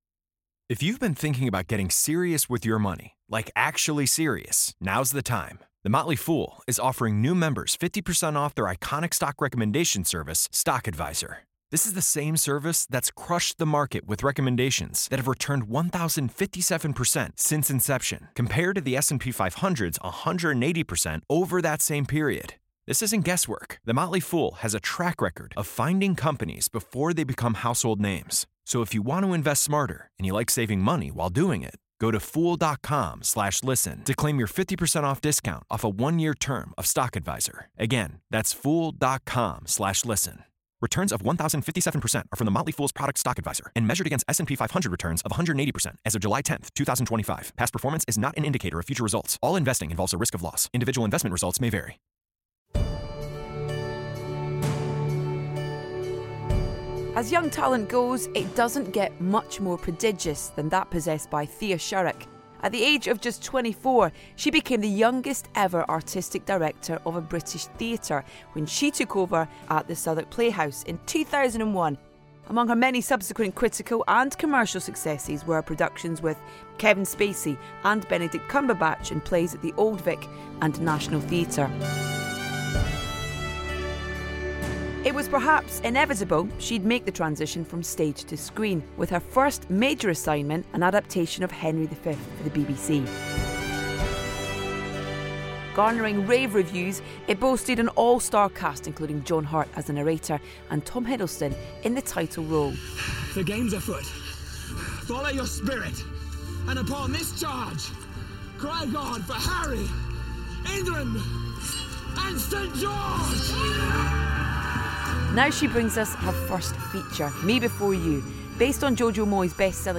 Again, there's plenty of Craig's music to enjoy here.